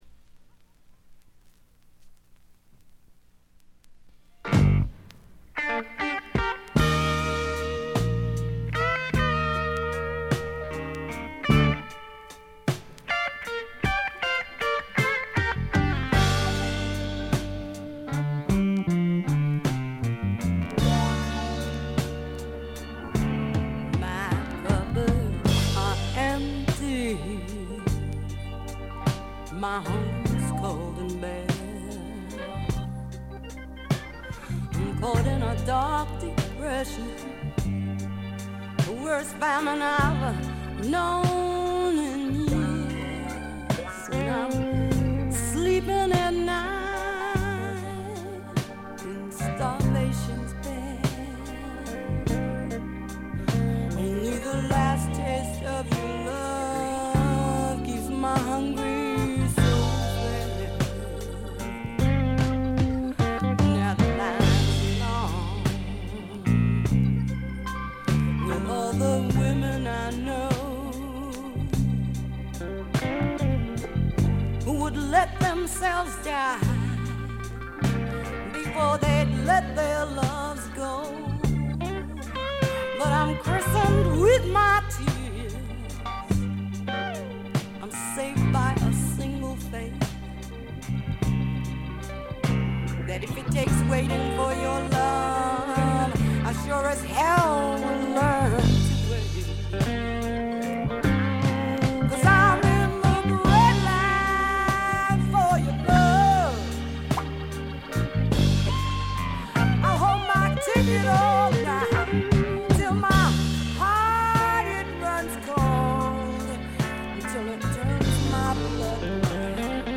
部分試聴ですがほとんどノイズ感無し。
ファンキーでタイト、全編でごきげんな演奏を繰り広げます。
試聴曲は現品からの取り込み音源です。